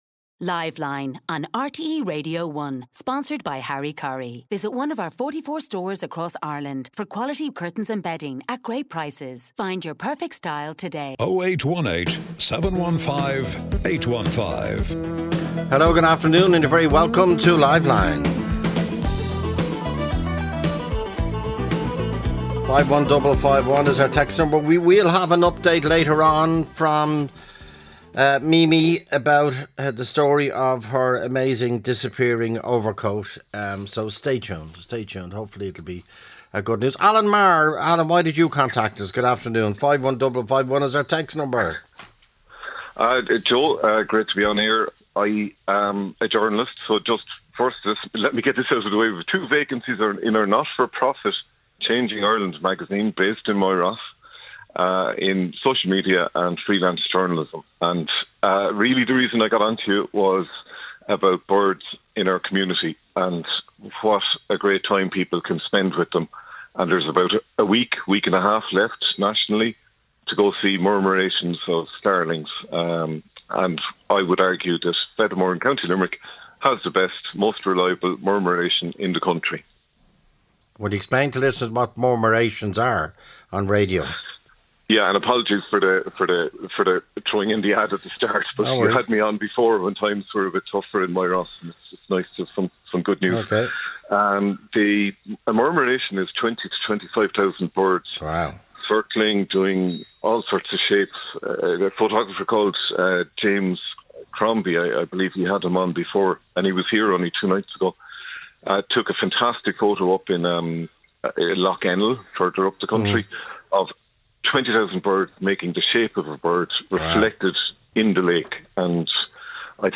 Joe Duffy talks to the Irish public about affairs of the day.